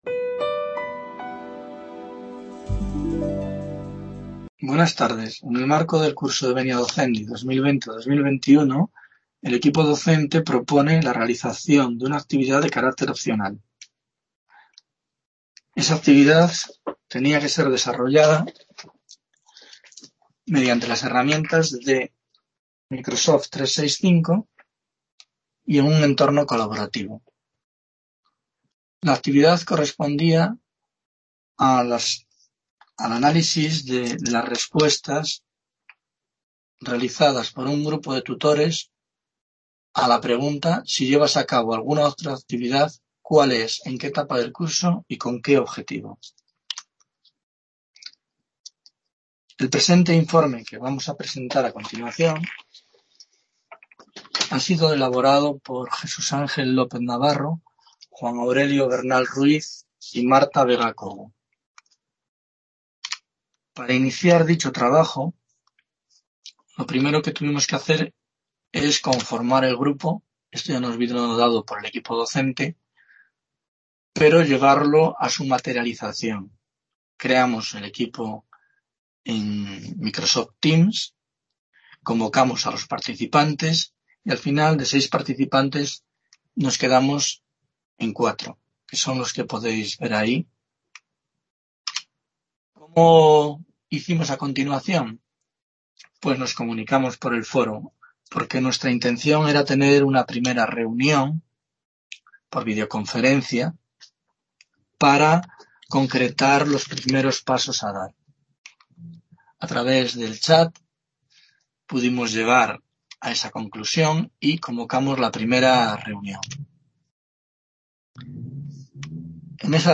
Presentación del trabajo realizado por el grupo IX en el marco del Curso de Venia Docendi 20/21
Video Clase